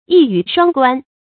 一語雙關 注音： ㄧ ㄧㄩˇ ㄕㄨㄤ ㄍㄨㄢ 讀音讀法： 意思解釋： 一個詞或一句話關涉到兩個意思；（即表面上是一個意思；暗中又含另一個意思）。